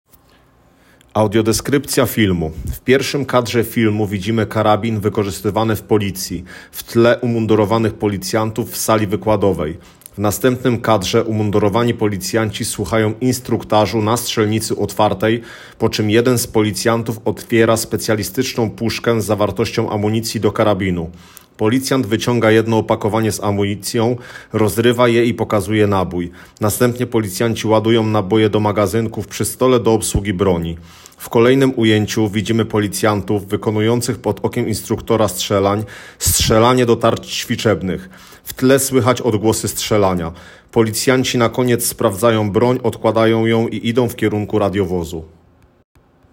Nagranie audio Audiodeskrypcja_filmu-szkolenie_na_strzelnicy_.m4a